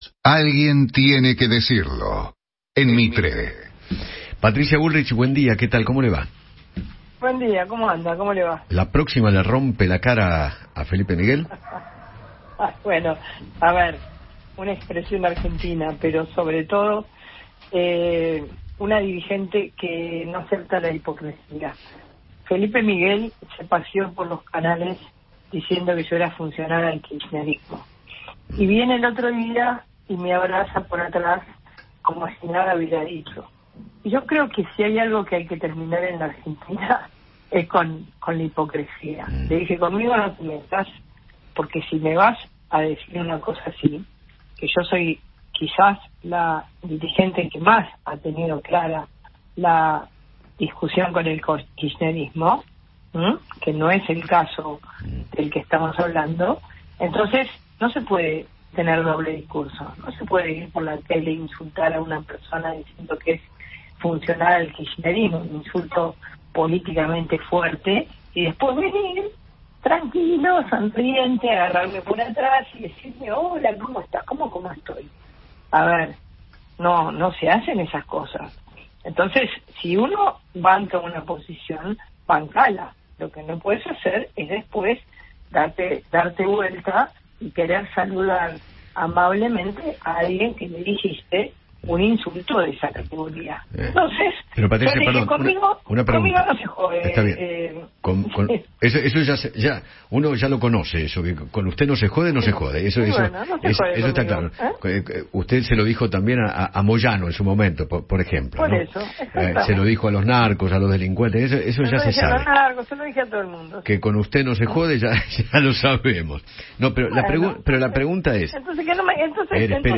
Patricia Bullrich, líder del PRO, dialogó con Eduardo Feinmann sobre el cruce que tuvo con el jefe de Gabinete porteño y expresó “conmigo no se jode”.